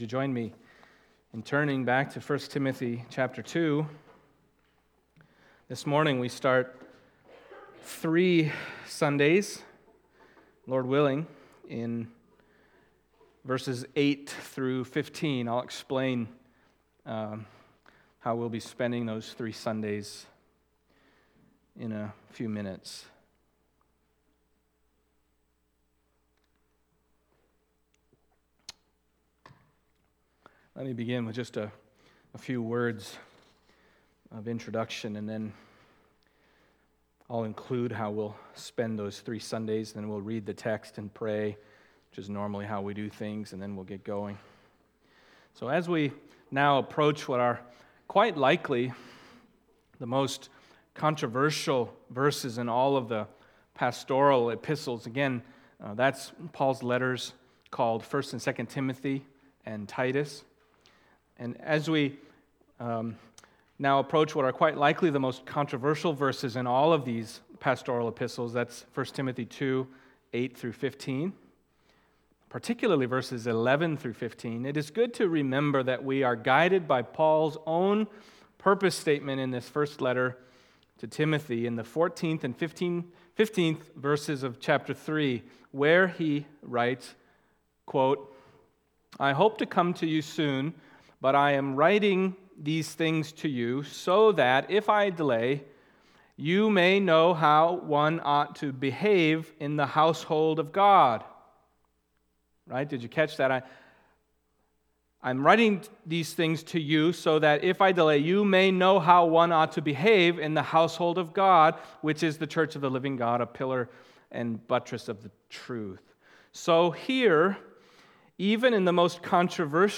1 Timothy 2:8-12 Service Type: Sunday Morning 1Timothy 2:8-12 « For Whom Did Jesus Die?